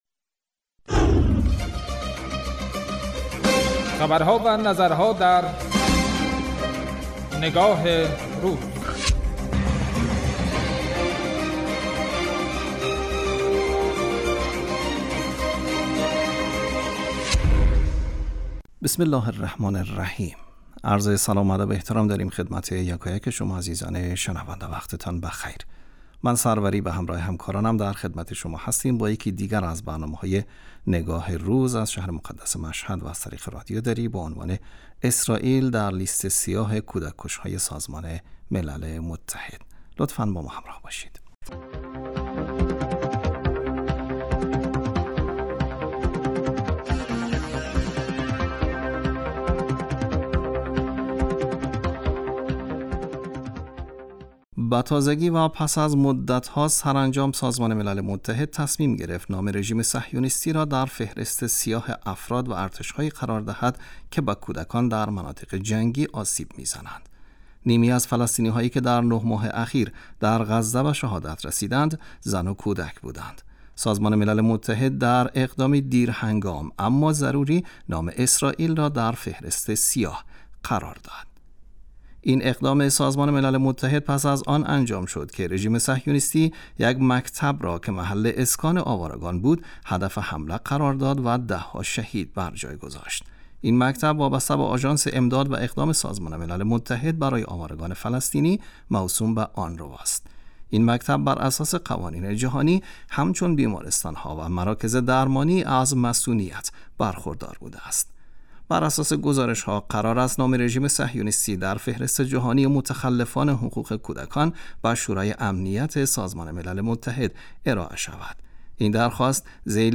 رادیو